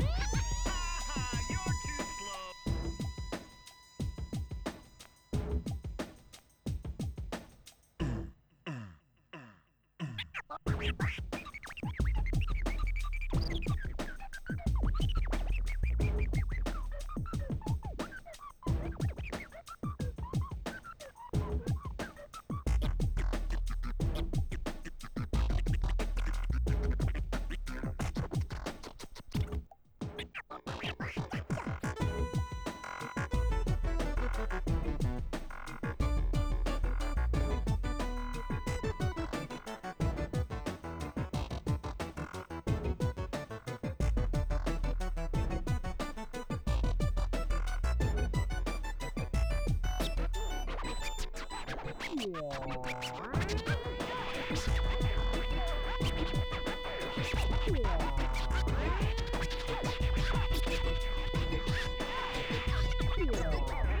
Background music [#]
As implied, this is mono ADPCM at 44.1KHz 16-bit.